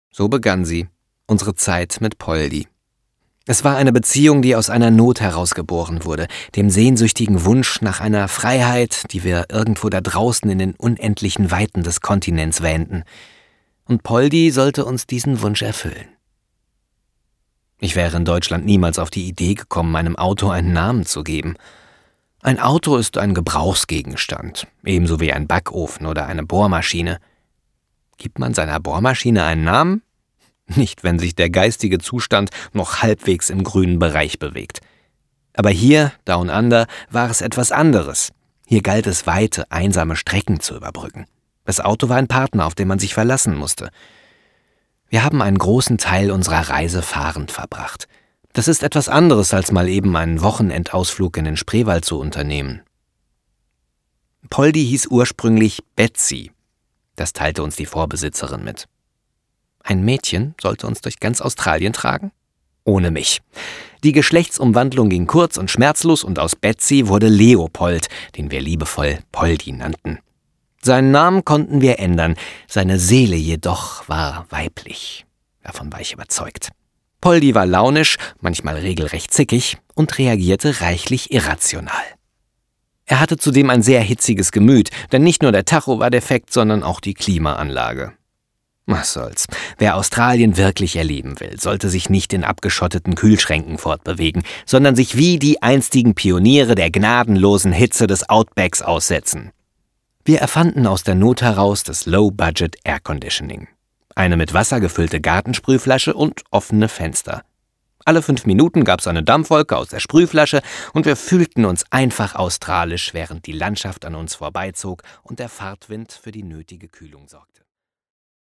Australien-Hörbuch
Hörbuch „So fühlt sich Freiheit an – Eine Reise durch Australien“, spannende, nachdenkliche und witzige Reiseerlebnisse auf 2 CDs im liebevoll gestalteten Digipack mit 8-seitigem Booklet, handgemachter Musik sowie Originalgeräuschen aus Australien.